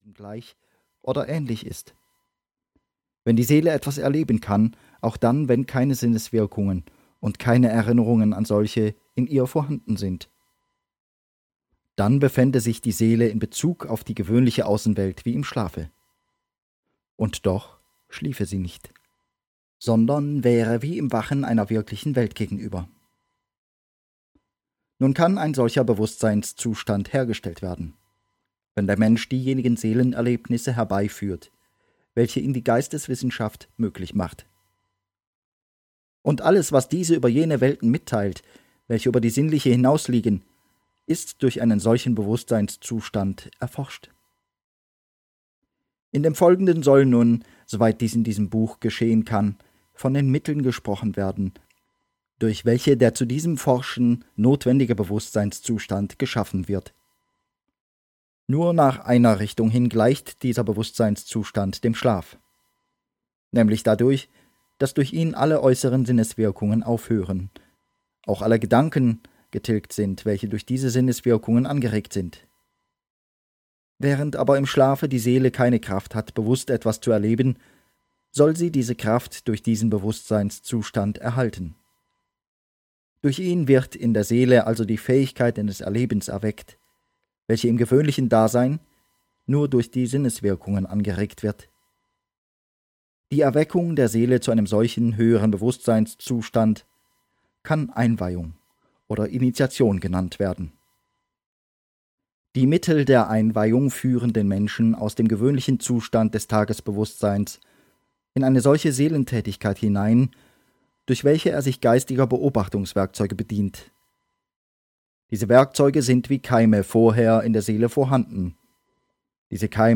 Die Erkenntnisse der höheren Welten - Rudolf Steiner - Hörbuch